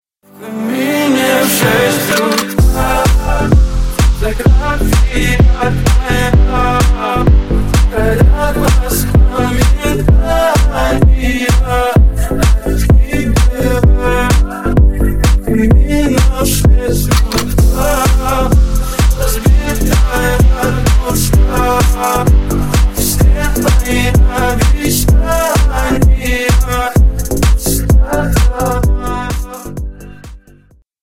Грустные Рингтоны
Рингтоны Ремиксы » # Поп Рингтоны